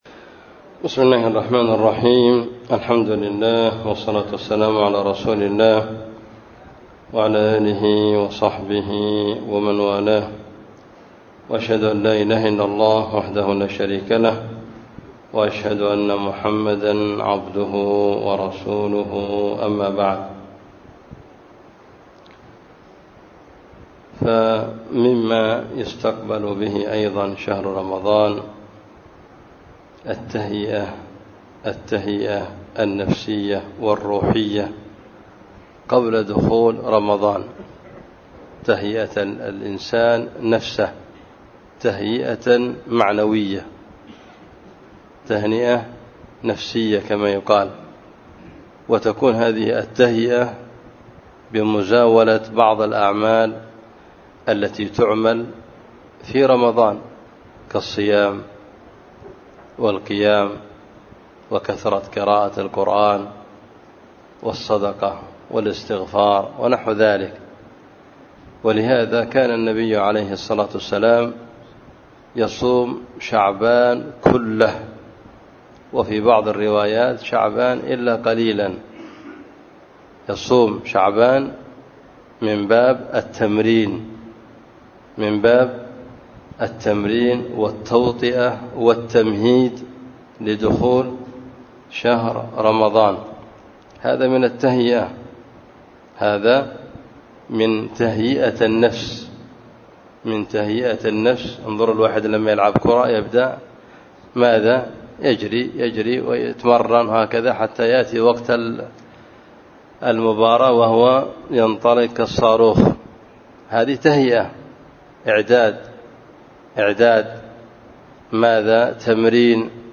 بدار الحديث في مسجد النصيحة بالحديدة